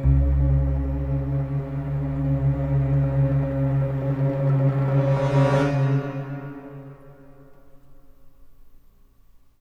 Index of /90_sSampleCDs/Best Service ProSamples vol.33 - Orchestral Loops [AKAI] 1CD/Partition C/CRESCENDOS